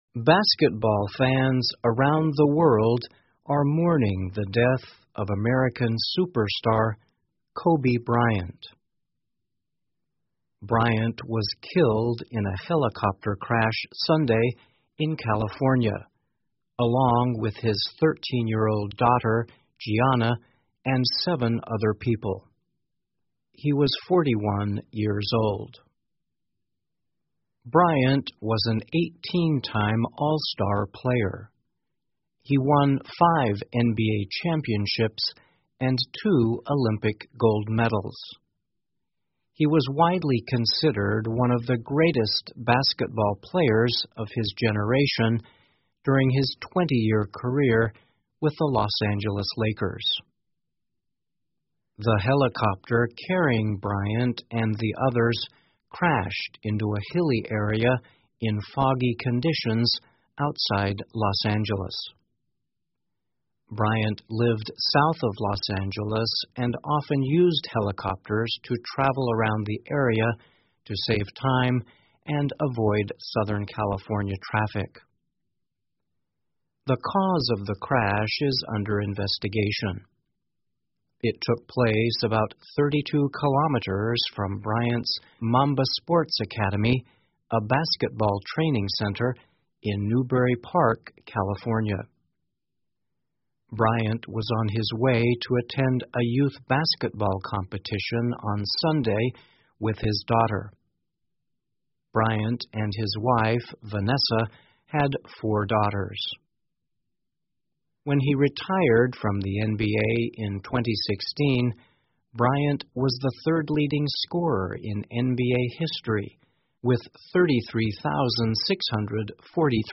VOA慢速英语2019 全球深切缅怀篮球巨星科比 听力文件下载—在线英语听力室